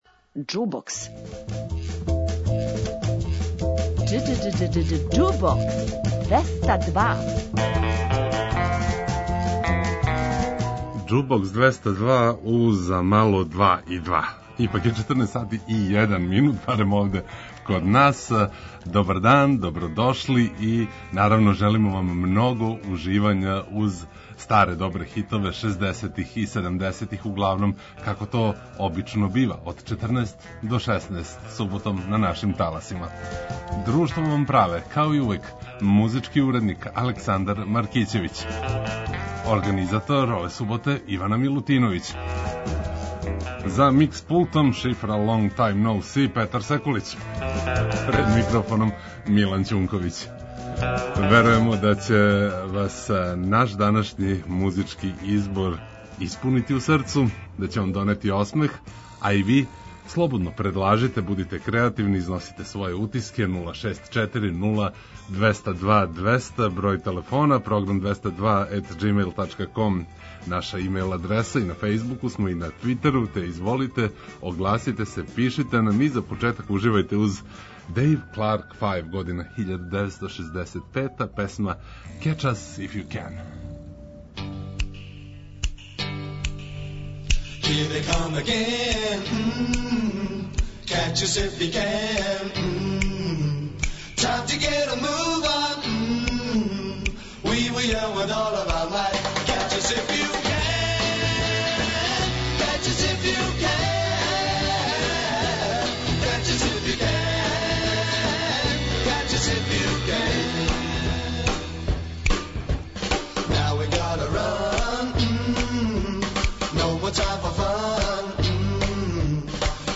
Уживајте у пажљиво одабраној старој, страној и домаћој музици.